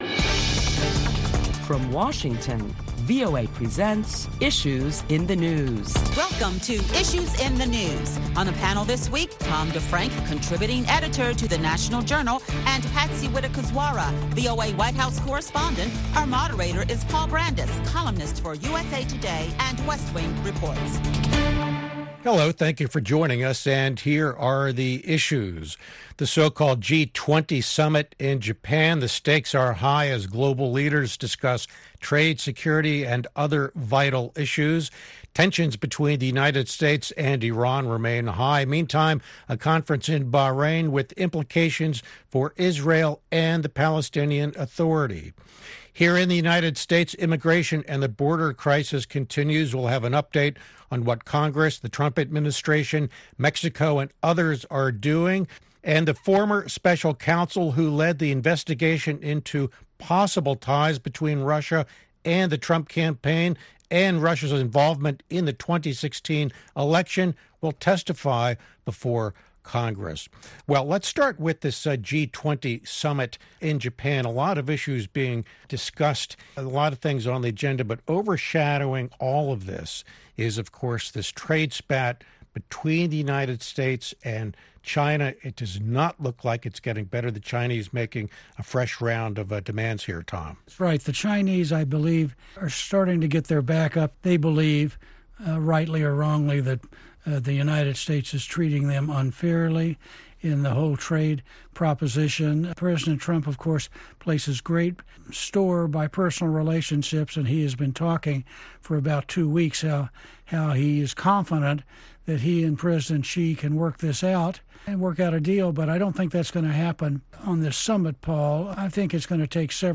Listen to a panel of prominent Washington journalists as they deliberate the week's headlines including President Trump talks with Chinese President Xi Jinping in Osaka, Japan, and the U.S. Senate approves a bipartisan legislation to address the humanitarian crisis along the U-S Mexico border.